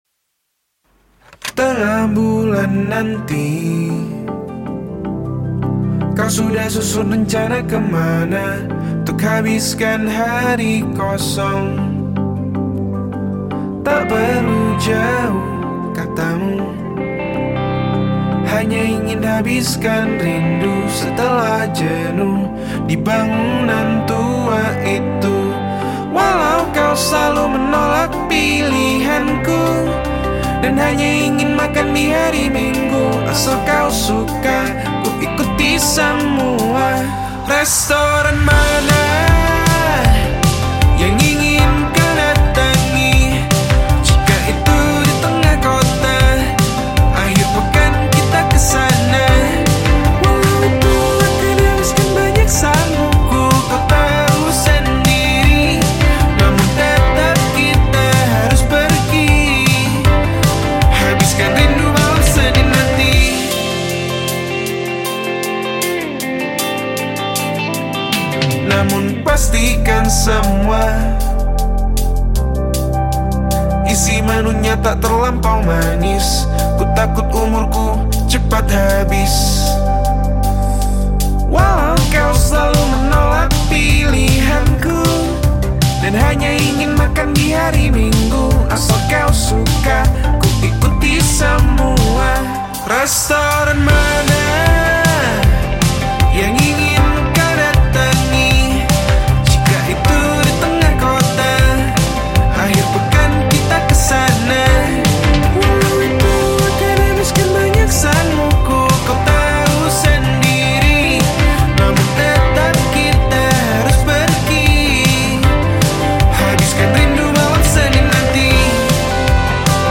Surabaya Pop